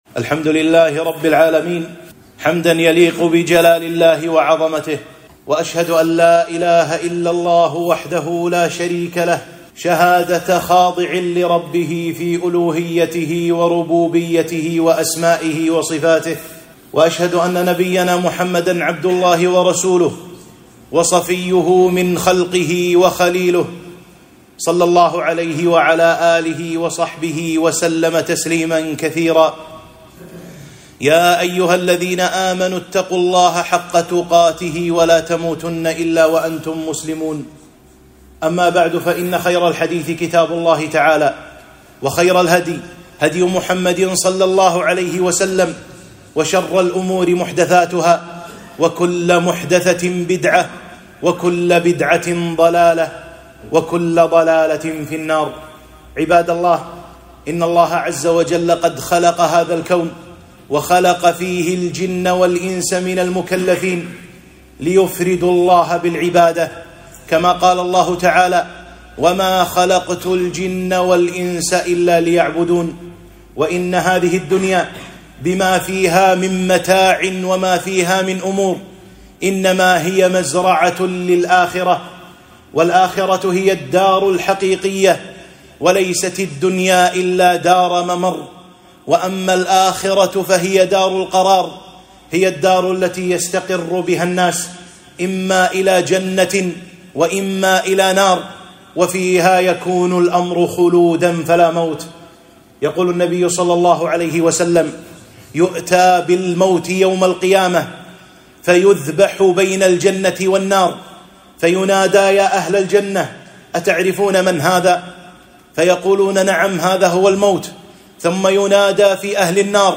خطبة-قيمة الدنيا عند الله سبحانه وتعالى